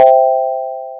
Free UI/UX sound effect: Success Chime.
Success Chime
Success Chime is a free ui/ux sound effect available for download in MP3 format.
028_success_chime.mp3